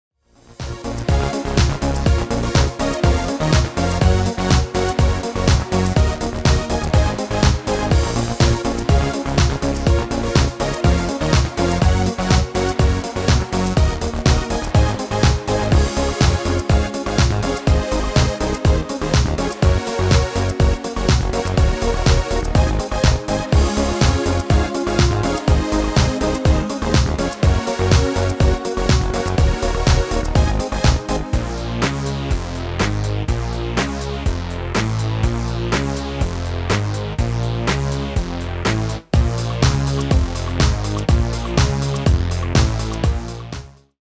Demo/Koop midifile
Genre: Dance / Techno / HipHop / Jump
Toonsoort: Bb
- Vocal harmony tracks
Demo's zijn eigen opnames van onze digitale arrangementen.